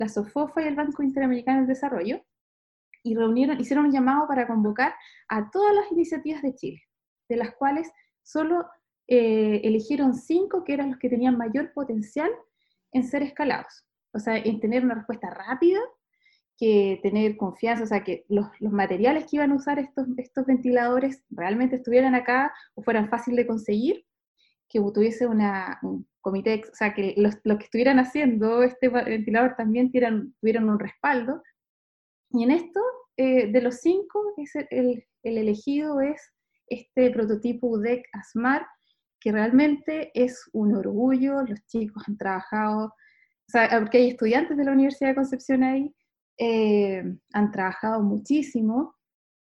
Para más información sobre el rol de ciencia y la tecnología en el manejo y control de la pandemia por Covid-19, puedes escuchar la entrevista completa a la Seremi Paulina Assmann, aquí: